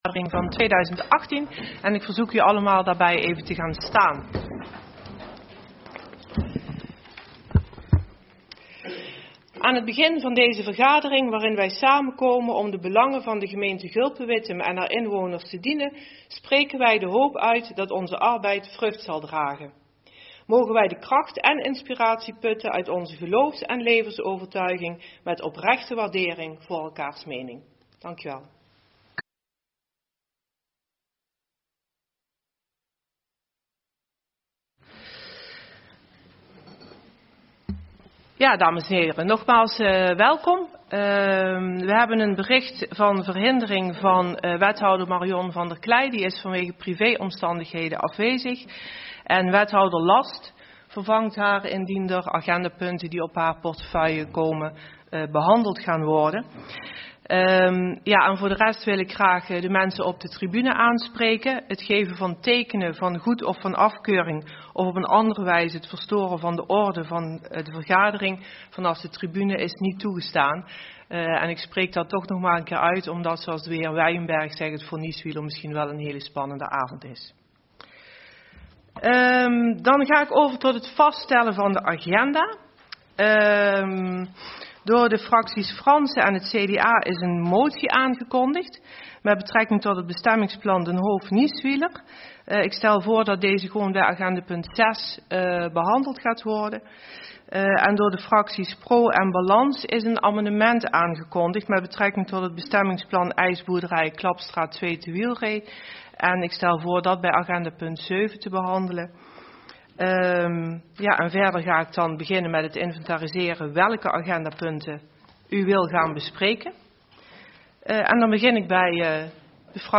Agenda GulpenWittem - Raadsvergadering donderdag 20 december 2018 19:30 - 22:30 - iBabs Publieksportaal